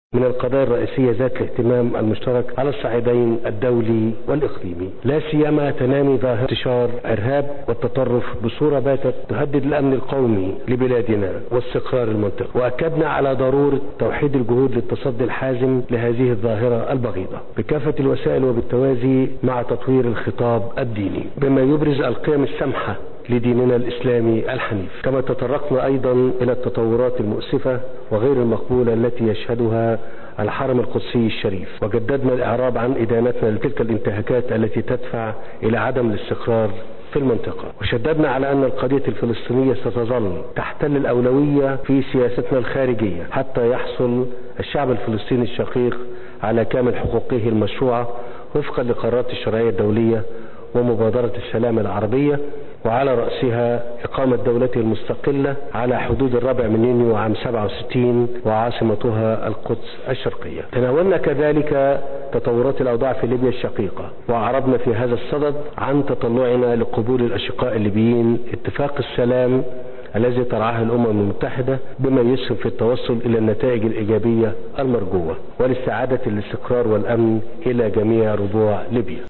قال الرئيس المصري عبد الفتاح السيسي خلال مؤتمر صحفي اليوم الأحد عقب محادثات مع الباجي قايد السبسي، إن القاهرة وتونس تتطلعان لقبول الأطراف الليبية اتفاق السلام الذي رعته الأمم المتحدة، لإنهاء الأزمة السياسية بين حكومتي طبرق وطرابلس.